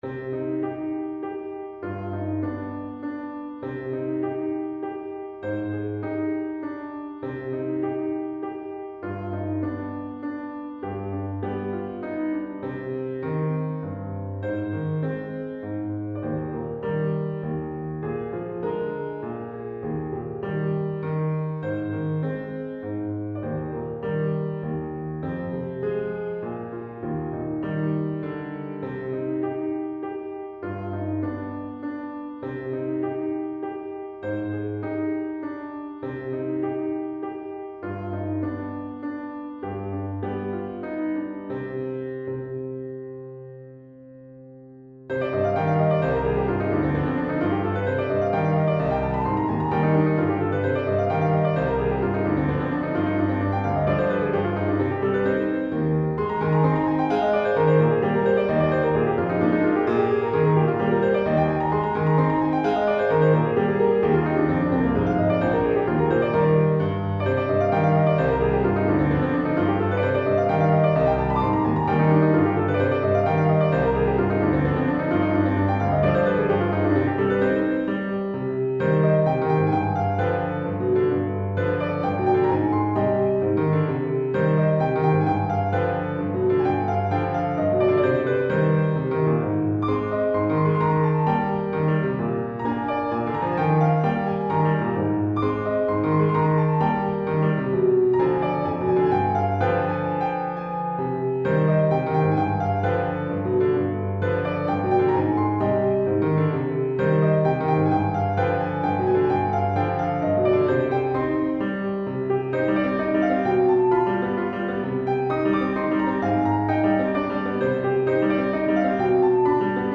Variations on a Theme from 'The Lady Vanishes' - Piano Music, Solo Keyboard - Young Composers Music Forum
This is a little set of piano variations on a pseudo-'folk song' that plays a major role in the plot of Hitchock's The Lady Vanishes. It's not a very serious nor ambitious work, and as it stands, I think it's likely in need of revision.